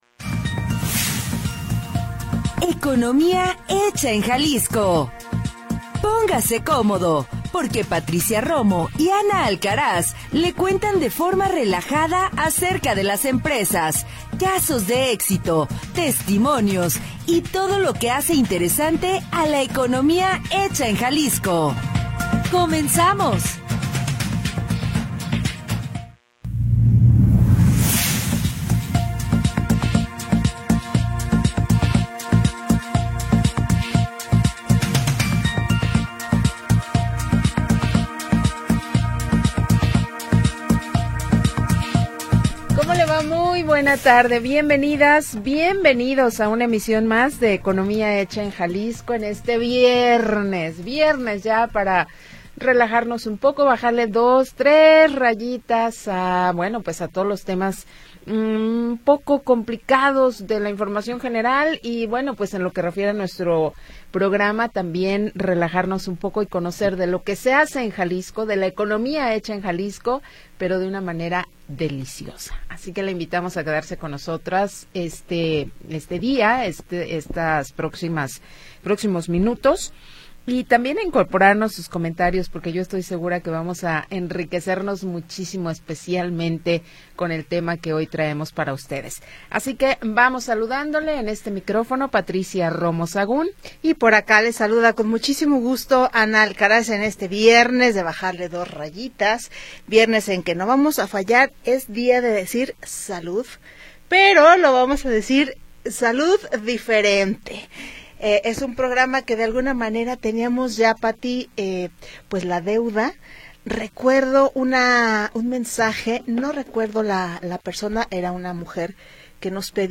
le cuentan de forma relajada acerca de las empresas, casos de éxito, testimonios y todo lo que hace interesante a la economía hecha en Jalisco.
Programa transmitido el 27 de Junio de 2025.